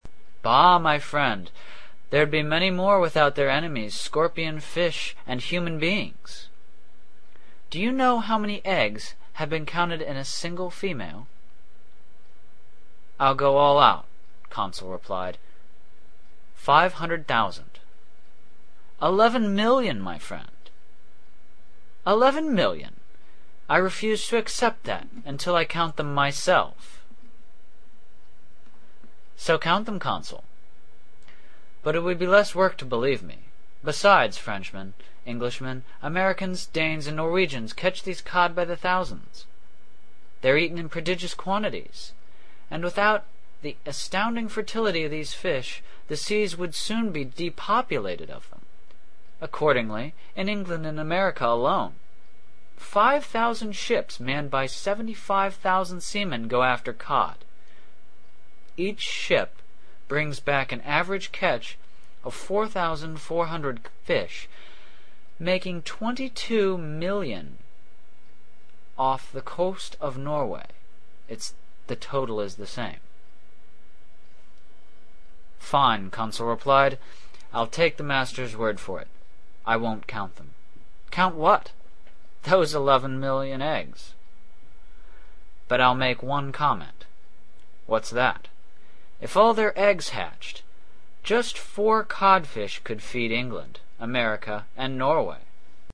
英语听书《海底两万里》第530期 第33章 北纬47.24度, 西经17.28度(5) 听力文件下载—在线英语听力室